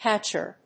/hæʃ''jʊə(米国英語)/